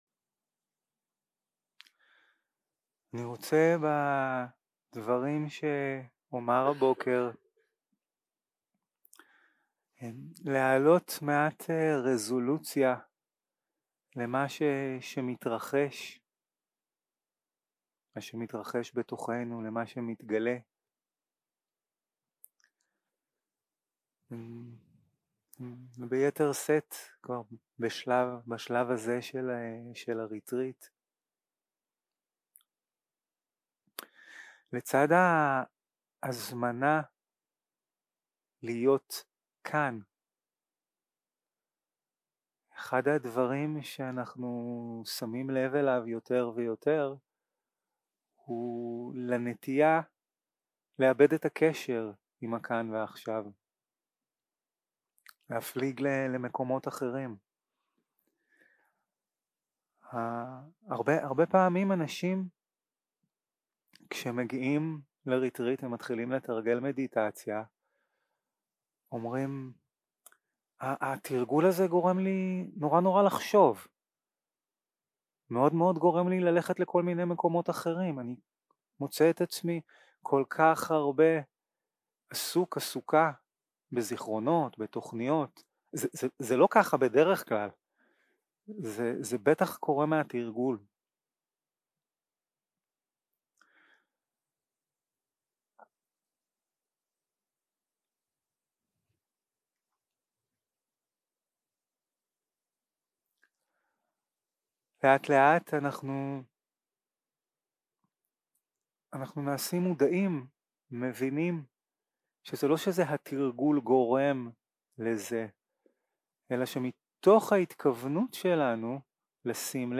יום 3 - הקלטה 5 - בוקר - הנחיות למדיטציה - גוון של הרגשה (ודנא) 0:00 0:00 סוג ההקלטה: Dharma type: Guided meditation שפת ההקלטה: Dharma talk language: Hebrew